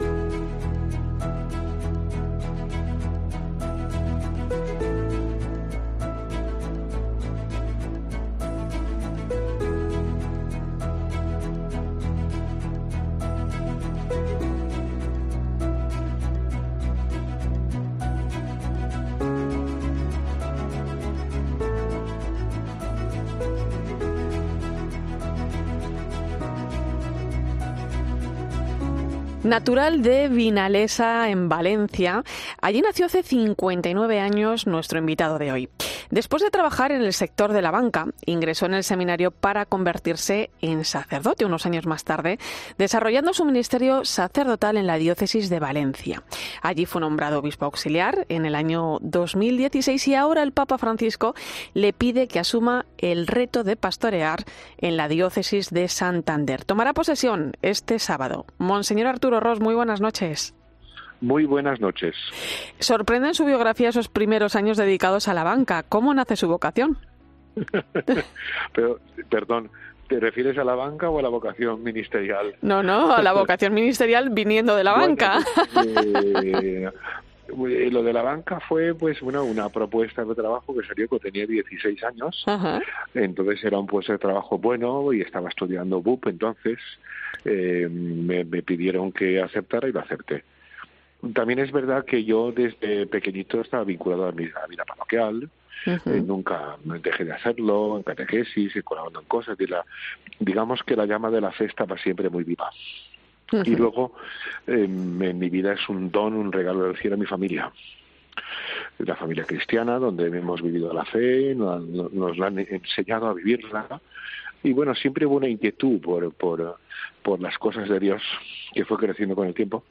En la víspera de su toma de posesión, el nuevo obispo de Santander explica en La Linterna de la Iglesia los primeros pasos que dará al frente de la...
Esta noche nos ha atendido en los micrófonos de La Linterna de la Iglesia para explicarnos los retos a los que va a enfrentarse como pastor de esta diócesis.